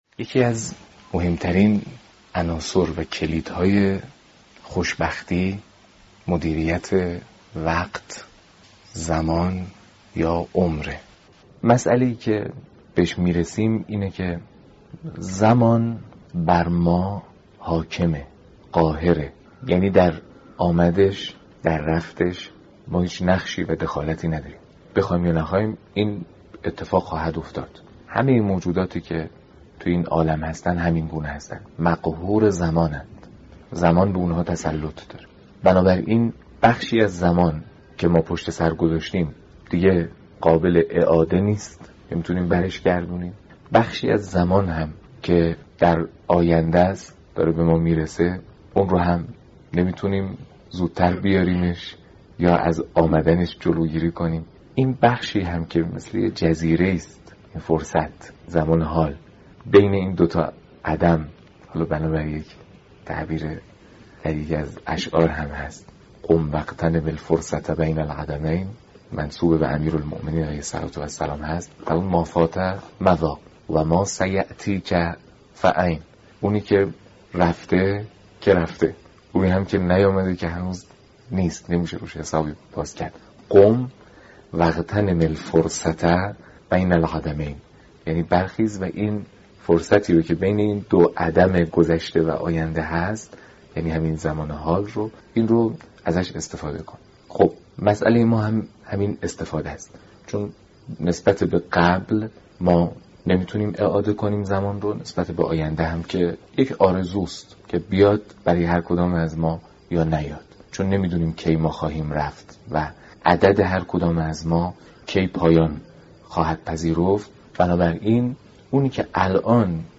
دانلود دومین جلسه از بیانات حجت الاسلام محمدجواد حاج علی اکبری با عنوان «مدیریت زمان»